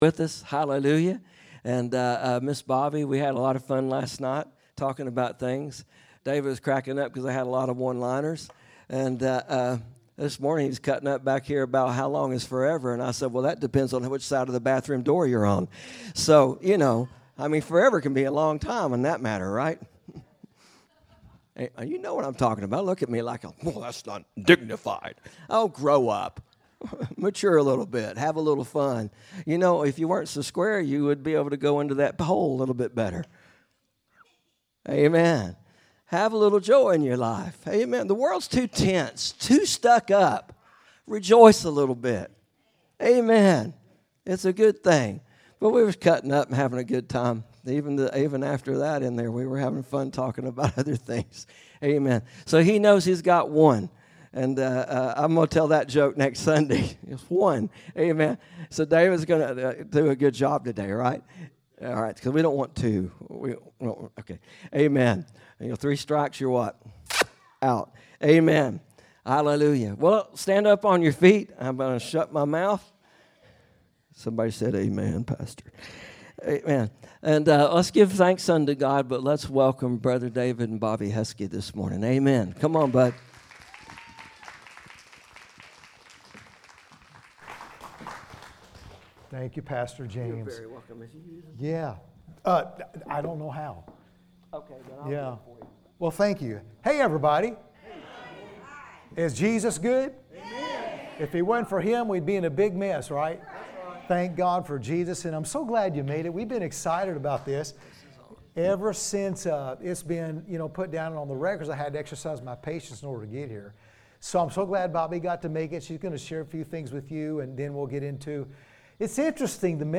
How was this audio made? AM Service